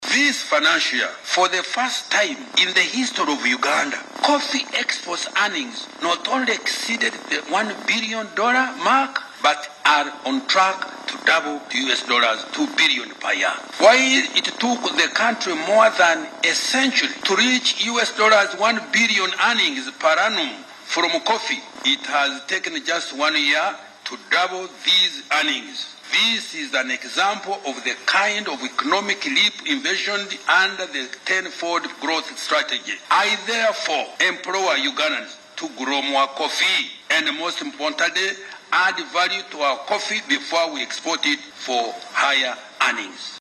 AUDIO: Minister Kasaija